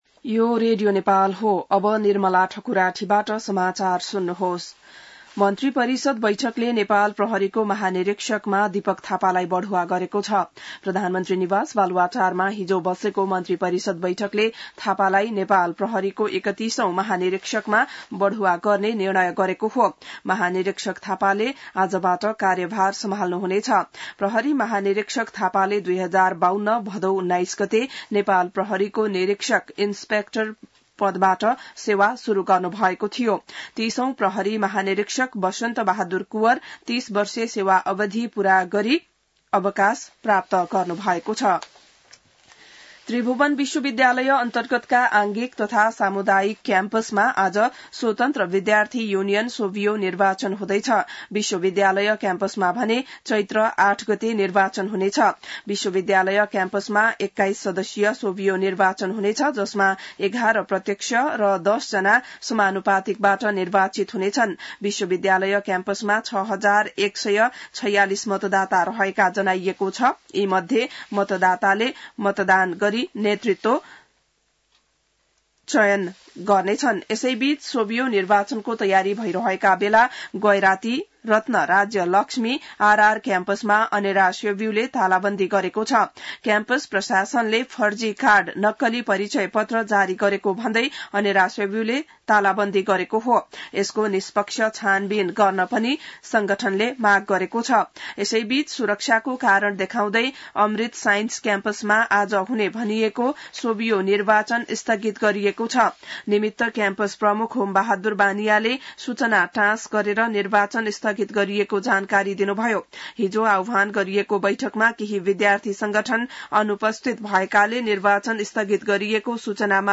बिहान ६ बजेको नेपाली समाचार : ५ चैत , २०८१